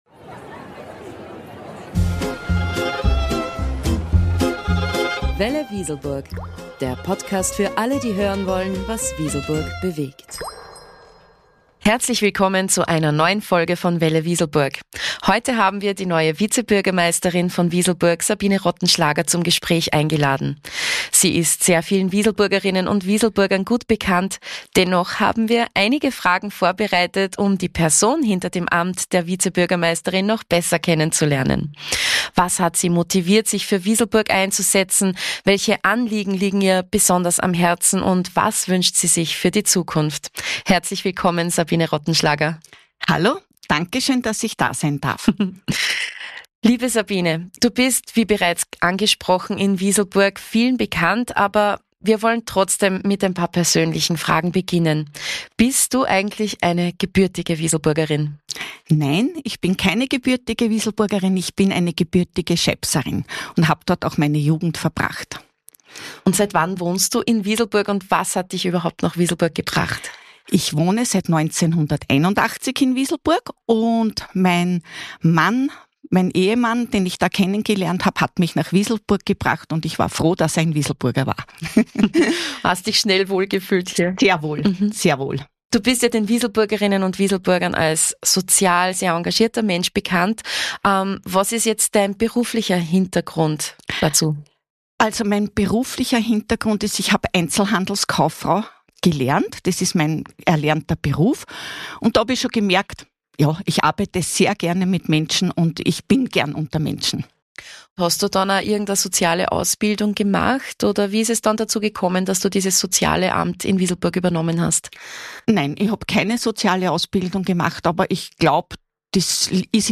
Folge 21 | Sabine Rottenschlager, die neue Vizebürgermeisterin von Wieselburg, im Gespräch ~ Welle.Wieselburg Podcast